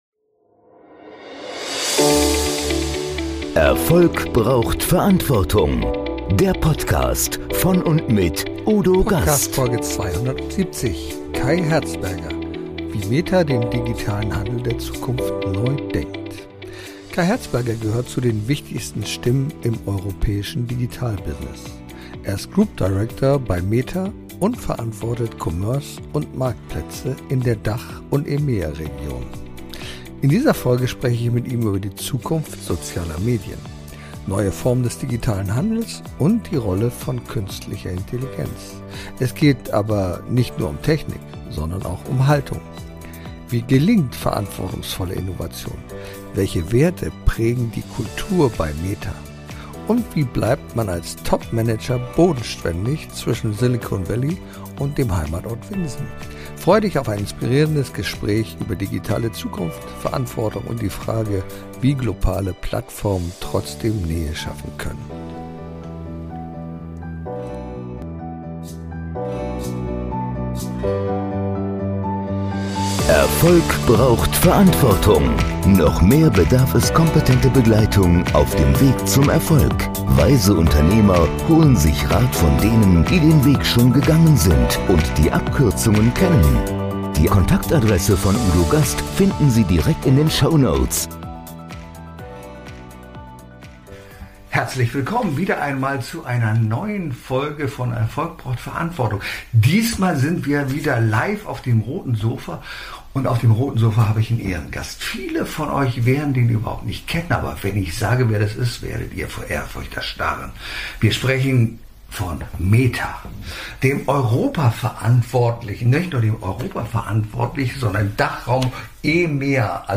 Freu Dich auf ein inspirierendes Gespräch über digitale Zukunft, Verantwortung und die Frage, wie globale Plattformen trotzdem Nähe schaffen können.